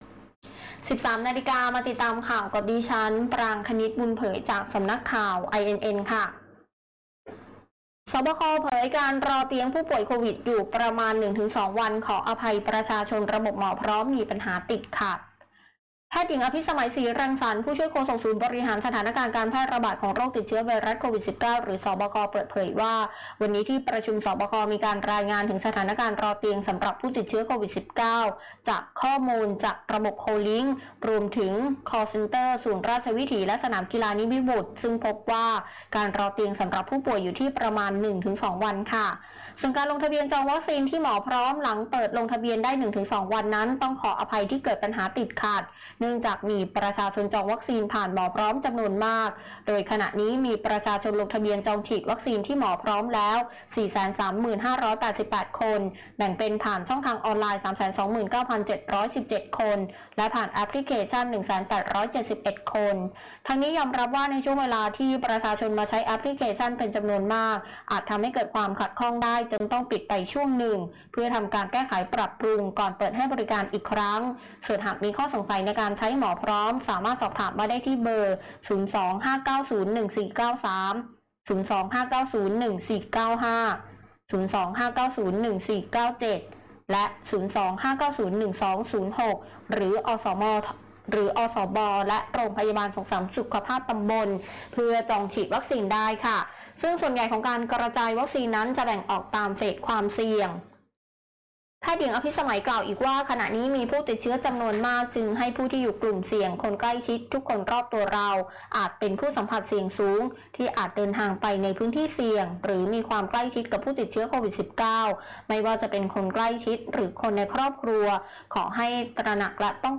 ข่าวต้นชั่วโมง 13.00 น.